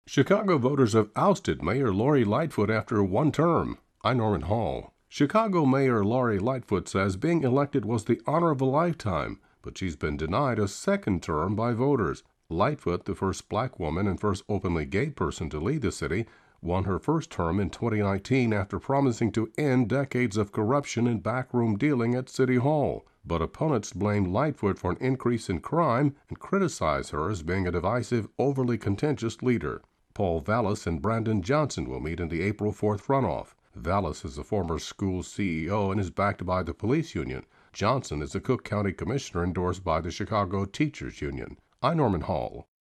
Election 2023-Chicago Mayor